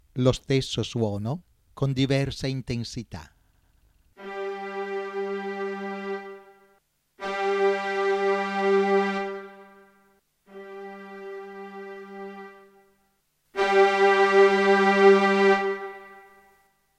2) un suono con diversa intensità
02_Lo_stesso_suono_con_diversa_intensita.wma